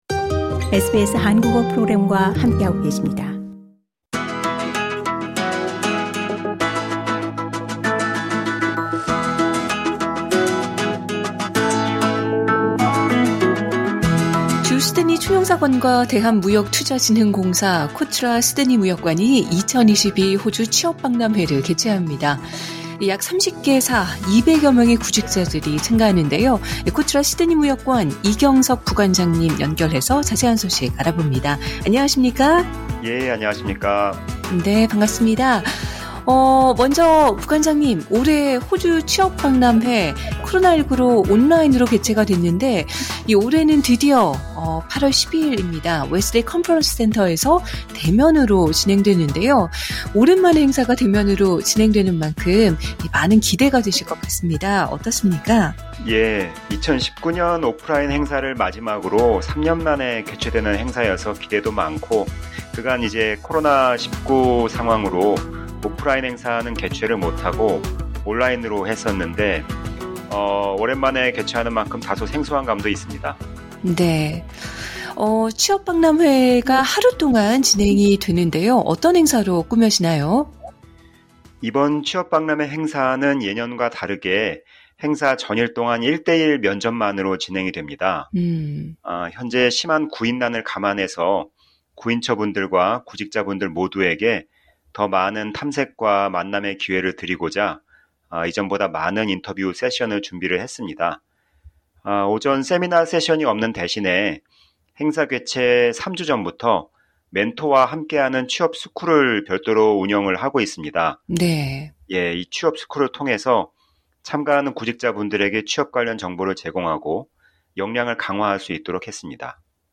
SBS 한국어 프로그램